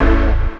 54_01_organ-A.wav